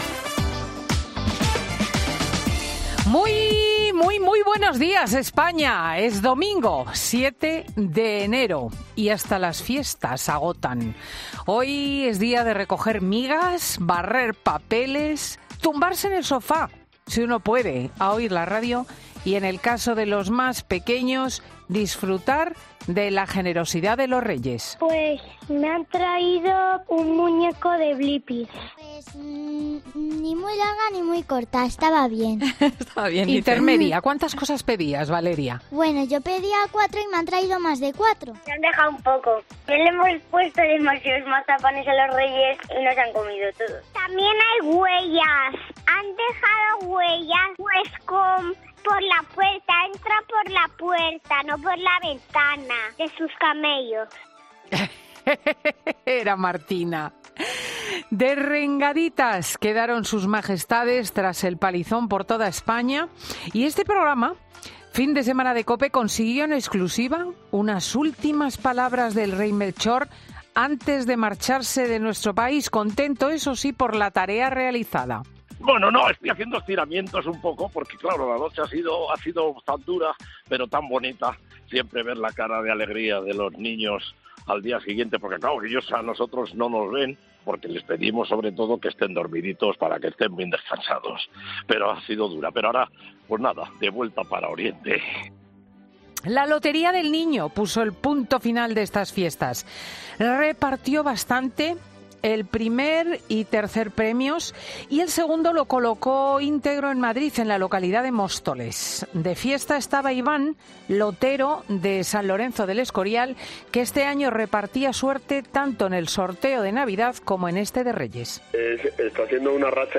AUDIO: La directora de 'Fin de Semana', Cristina López Schlichting, analiza las principales noticias de estos días como la Pascua Militar o la...
Monólogo